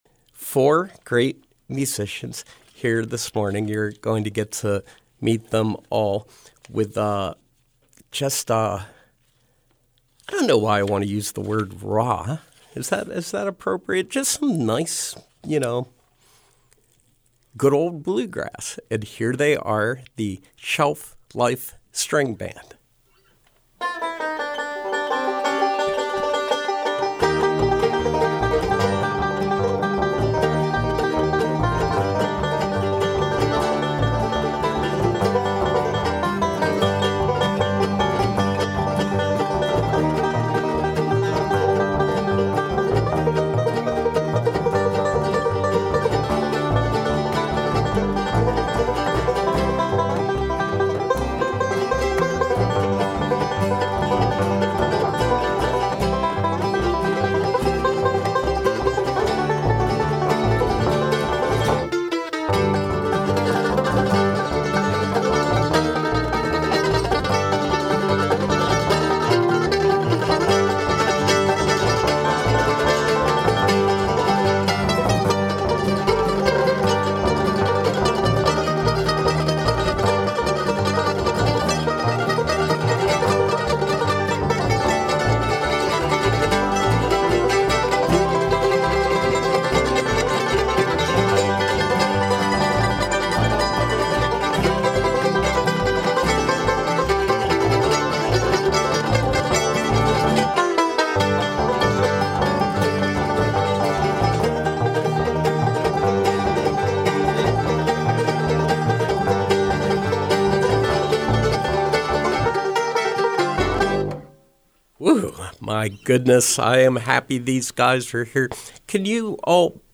Bluegrass
guitar
banjo
mandolin
double bass